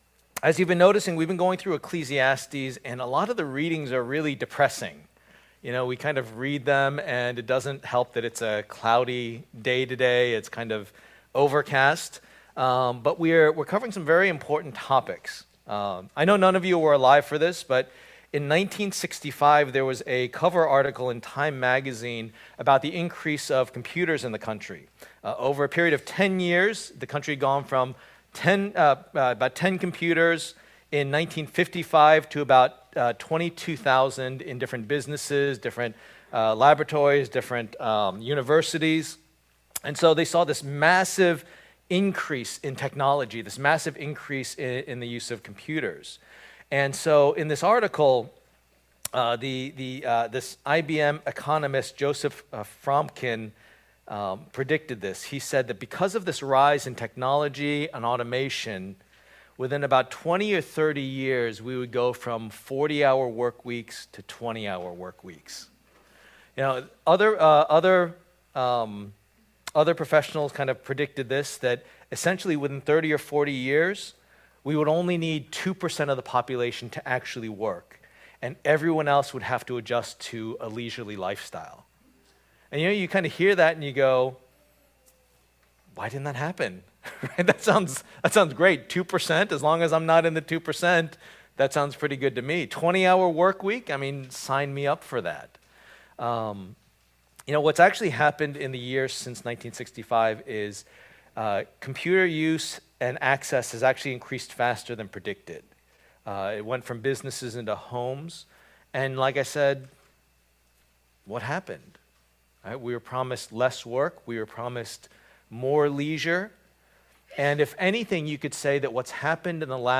Passage: Ecclesiastes 2:18-26 Service Type: Lord's Day